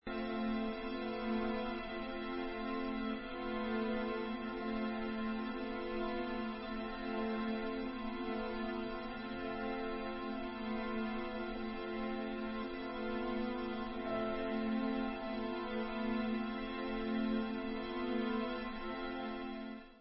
Tranquillissimo
sledovat novinky v kategorii Vážná hudba